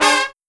FALL HIT10-L.wav